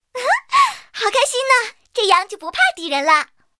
SU-26强化语音.OGG